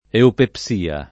eupepsia [ eupep S& a ]